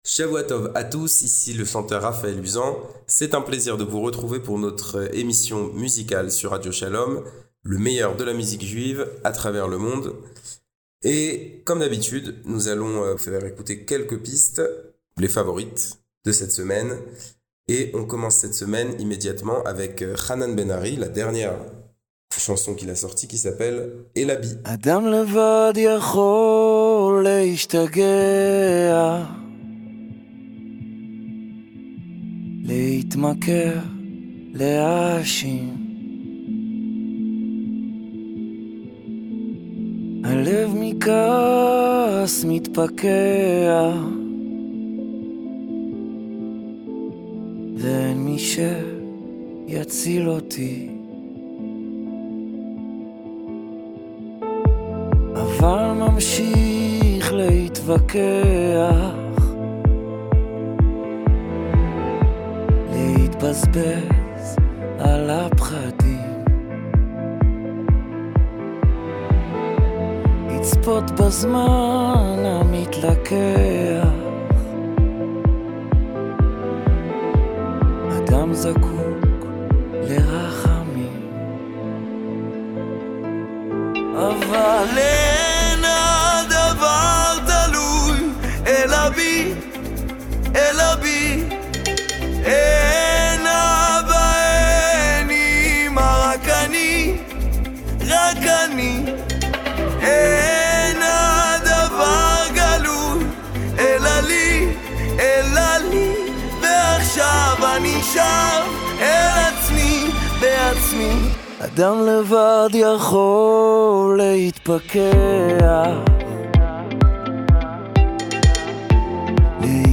Le meilleur de la musique juive, tous les Samedis soirs juste après Melavé Malka, aux alentours de 19h30 !